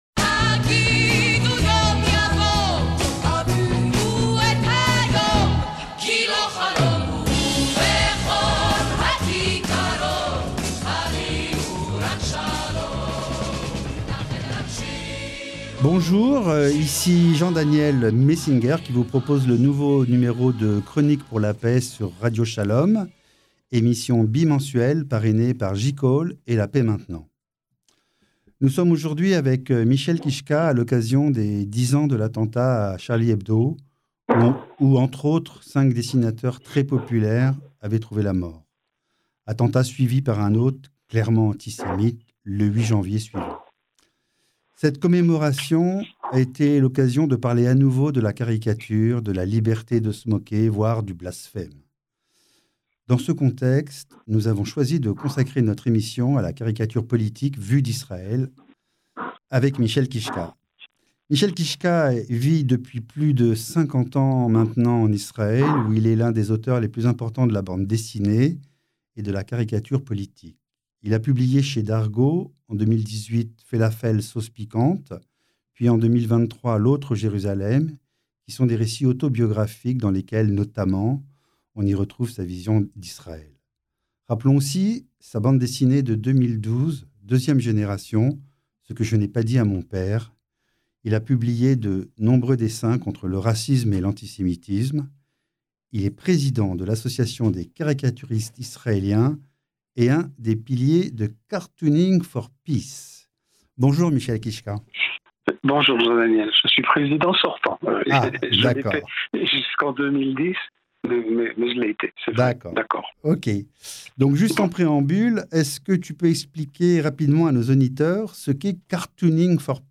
CLIQUER SUR LA FLÈCHE (LIEN) CI-DESSOUS POUR ÉCOUTER L’INTERVIEW SUR RADIO SHALOM (94.8 fm)